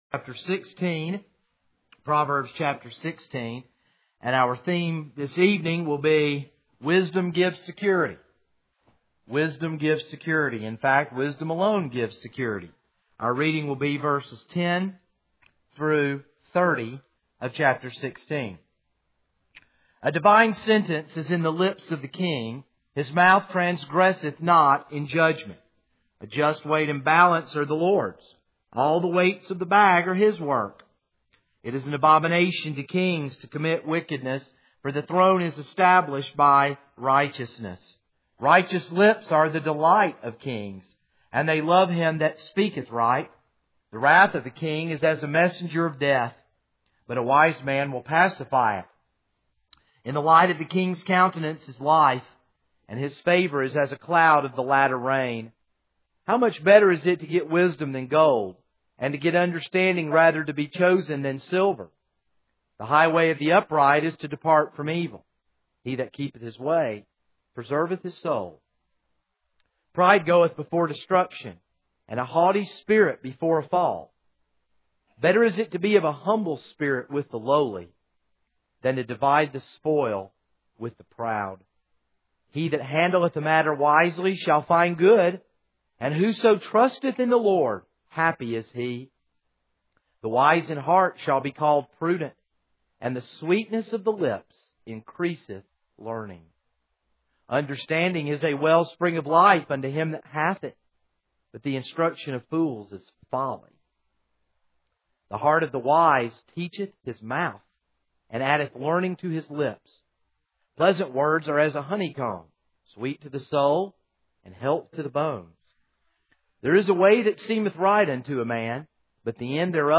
This is a sermon on Proverbs 16:10-30.